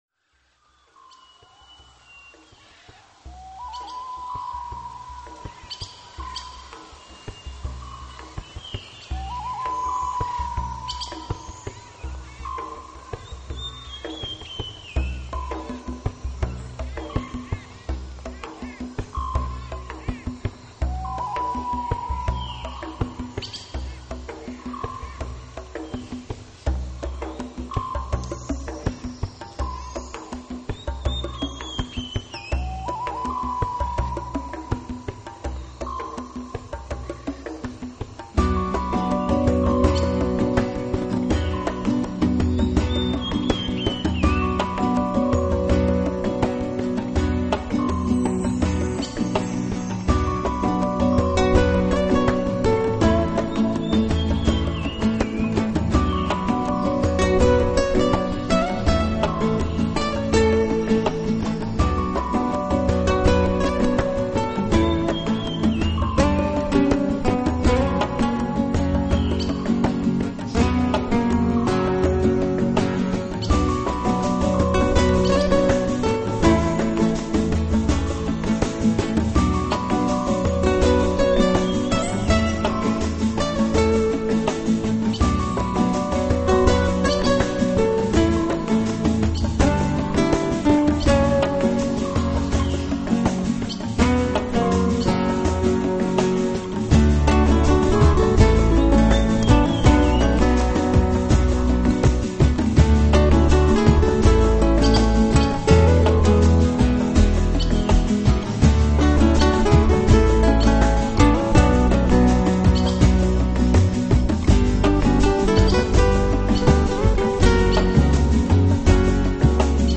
Жанр: New Age